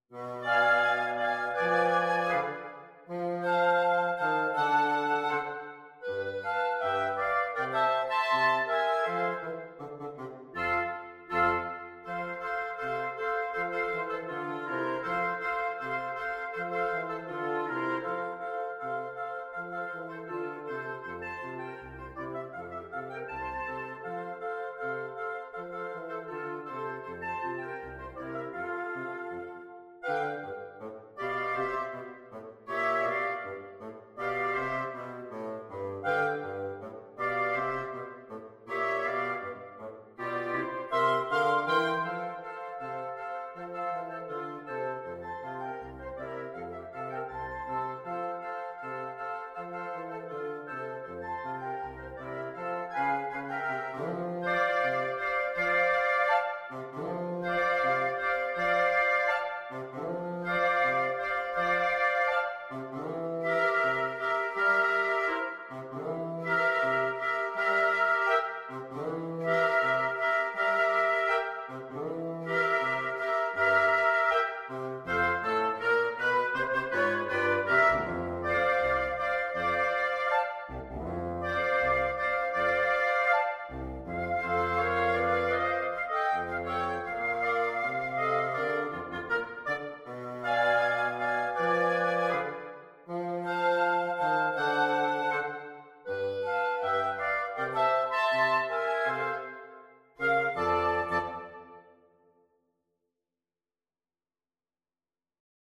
Free Sheet music for Wind Quartet
FluteOboeClarinetBassoon
F major (Sounding Pitch) (View more F major Music for Wind Quartet )
Allegro Moderato = c. 80 (View more music marked Allegro)
Jazz (View more Jazz Wind Quartet Music)